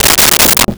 Desk Drawer Closed 01
Desk Drawer Closed 01.wav